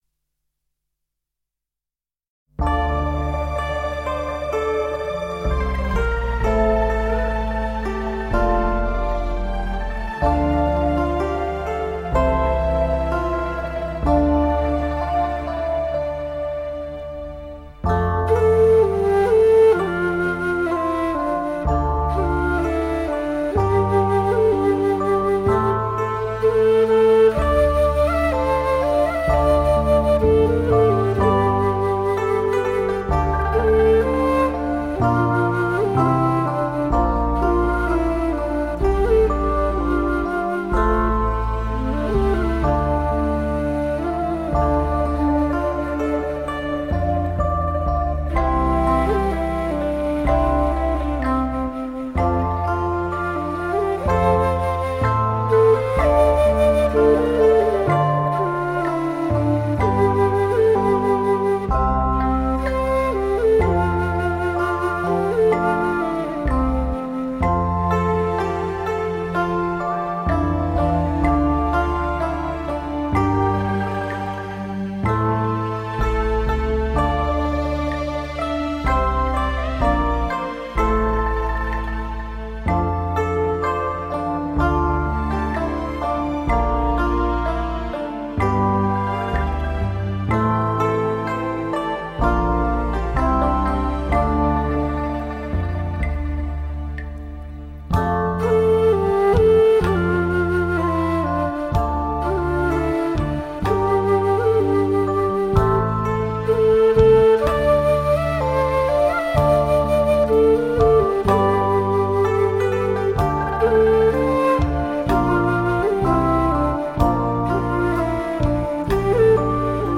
洞箫在中国民族乐器中是一种音色优美的吹奏乐器，它音色淳厚、柔和、幽静。